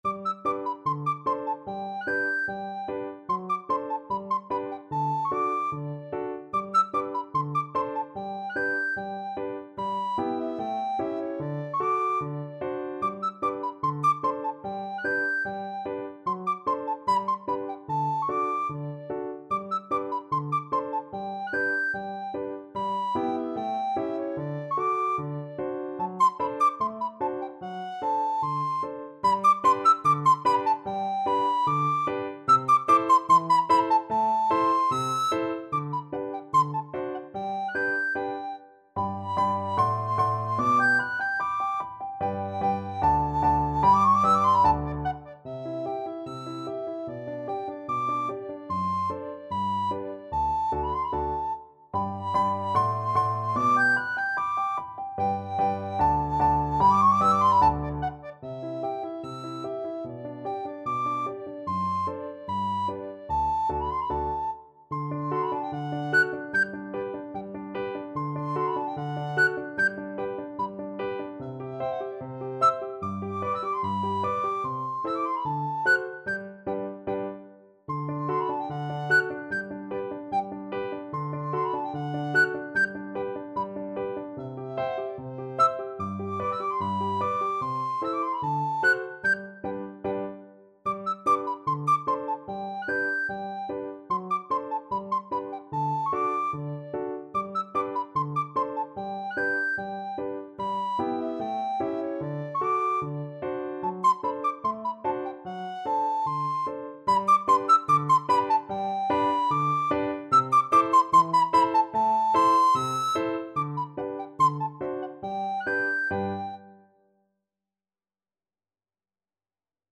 Free Sheet music for Soprano (Descant) Recorder
2/2 (View more 2/2 Music)
Allegretto = 74
Classical (View more Classical Recorder Music)